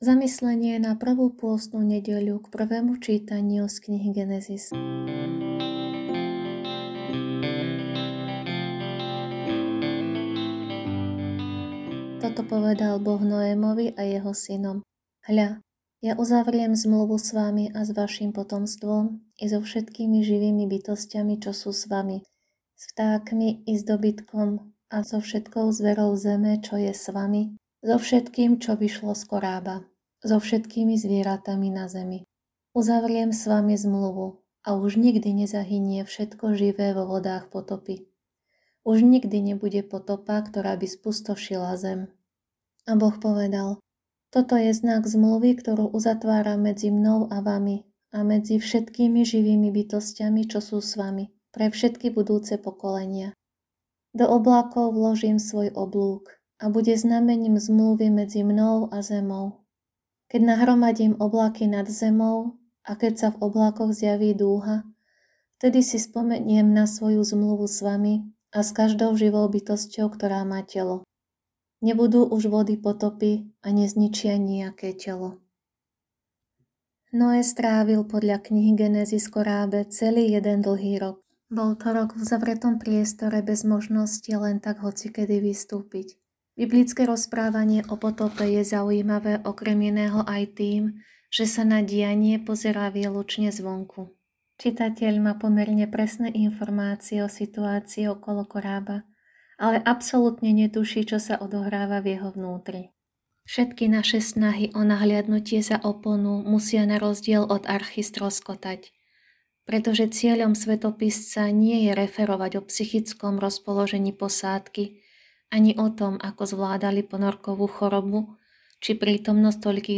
Audionahrávka zamyslenia…
Hudba: ESPé – V mojom srdci znie